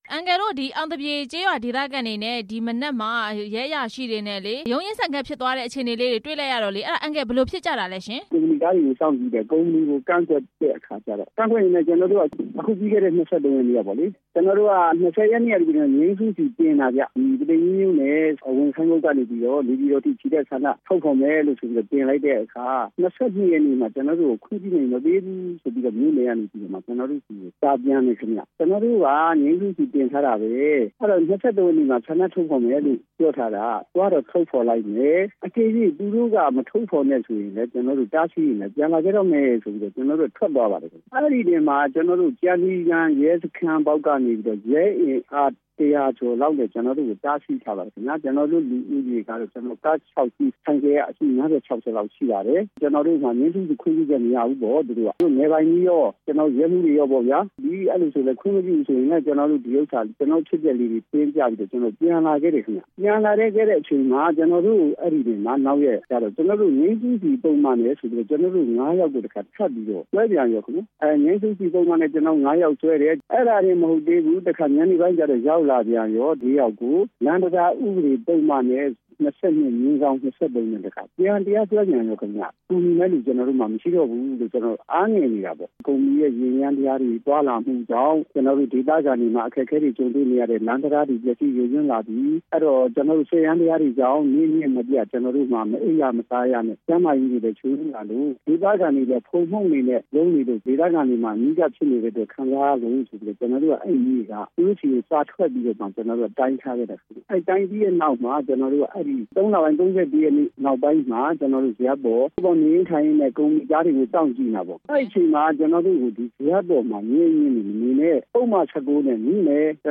ပုသိမ်ကြီး ကျောက်မီးသွေးသုံးစက်ရုံစီမံကိန်း ဆန္ဒပြပွဲအကြောင်း မေးမြန်းချက်